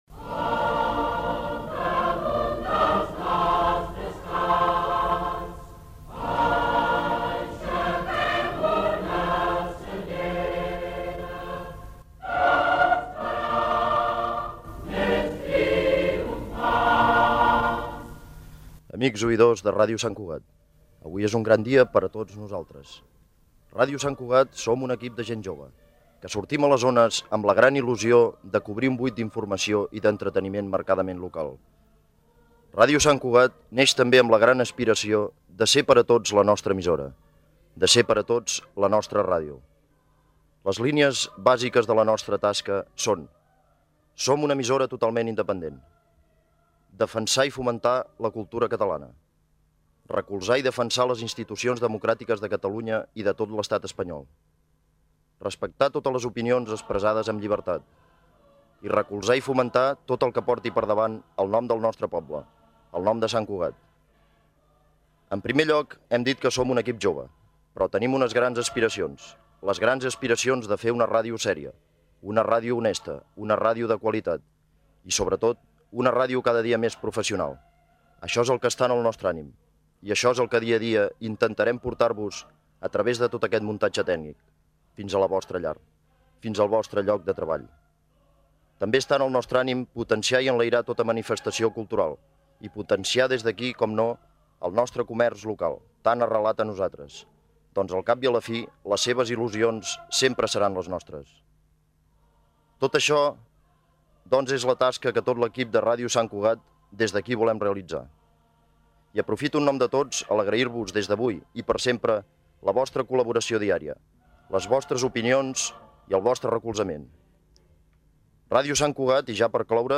"El cant de la senyera" i primeres paraules en l'emissió inaugural de Ràdio Sant Cugat en la seva etapa com a emissora local comercial
FM